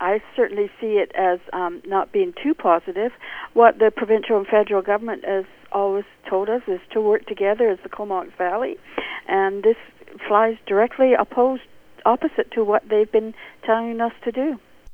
Cumberland Mayor Leslie Baird has some major concerns on that…